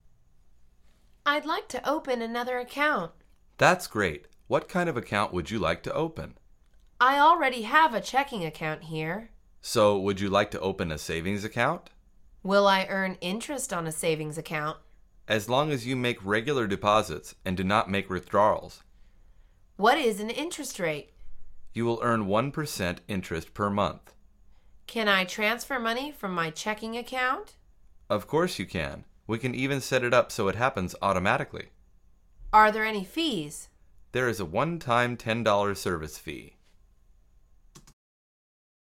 مجموعه مکالمات ساده و آسان انگلیسی: باز کردن یک حساب پس انداز